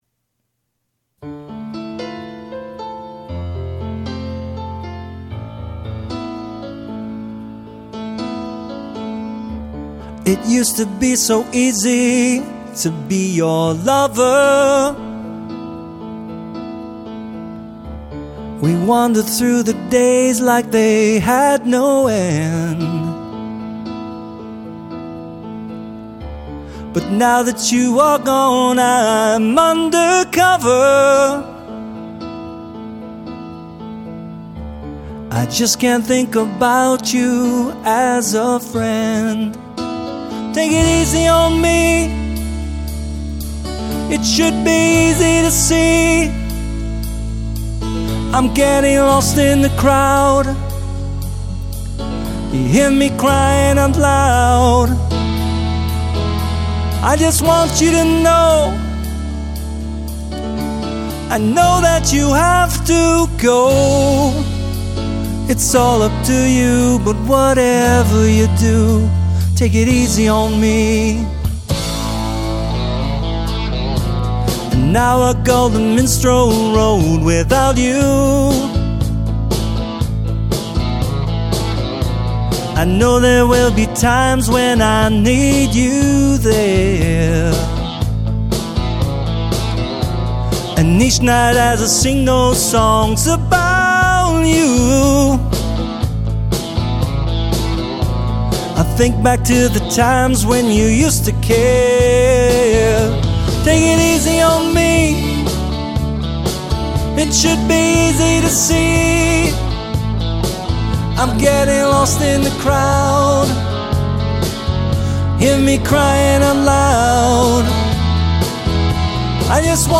I kind of cut it off abruptly at the end.